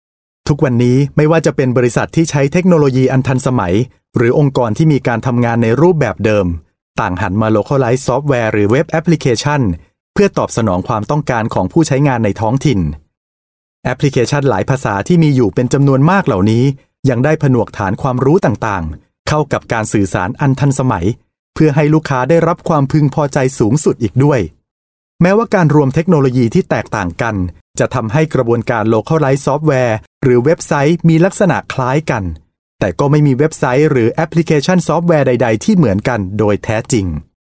Voice Sample: Voice Sample
We use Neumann microphones, Apogee preamps and ProTools HD digital audio workstations for a warm, clean signal path.